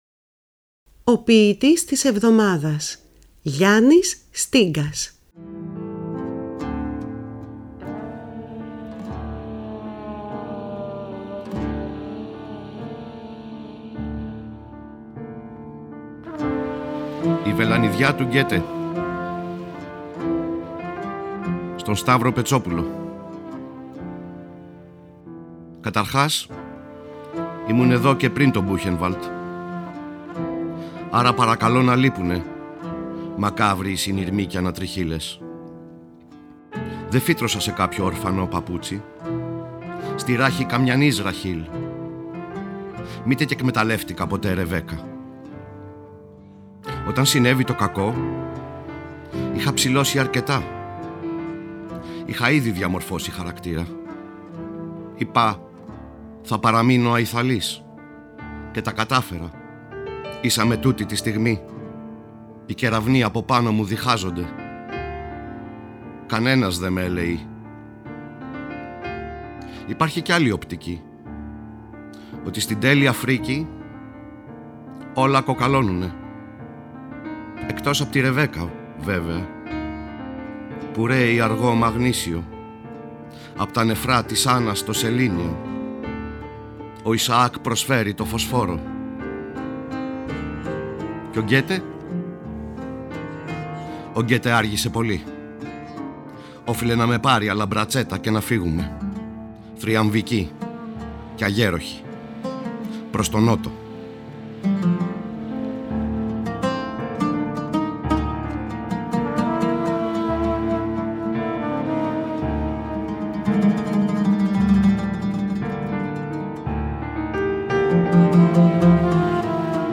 Κάθε εβδομάδα είναι αφιερωμένη σ’ έναν σύγχρονο Έλληνα ποιητή ή ποιήτρια, ενώ δεν απουσιάζουν οι ποιητές της Διασποράς. Οι ίδιοι οι ποιητές και οι ποιήτριες επιμελούνται τις ραδιοφωνικές ερμηνείες. Παράλληλα τα ποιήματα «ντύνονται» με πρωτότυπη μουσική, που συνθέτουν και παίζουν στο στούντιο της Ελληνικής Ραδιοφωνίας οι μουσικοί της Ορχήστρας της ΕΡΤ, καθώς και με μουσικά κομμάτια αγαπημένων δημιουργών.